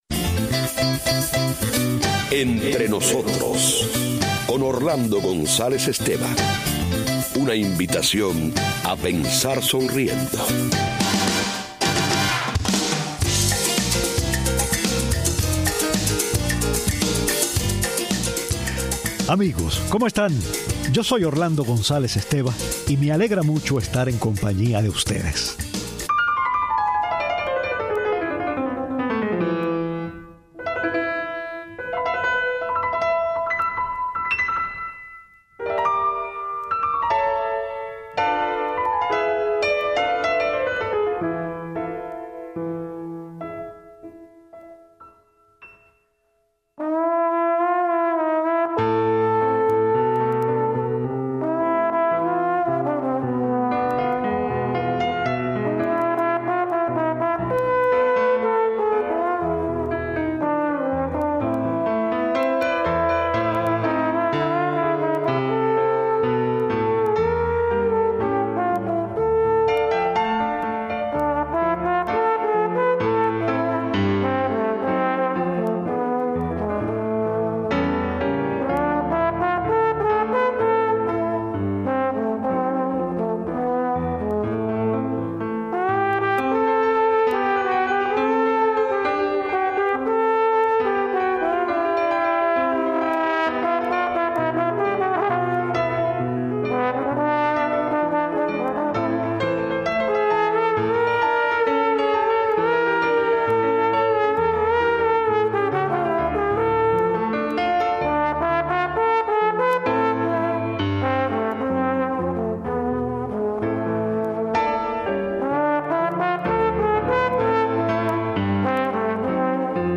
Una familia francoreana interpretando "Veinte años", un caramelero cantando en un autobús cubano, un niño que apenas sabe hablar tratando de entonar una canción y un puñado de correos hacen el programa de hoy.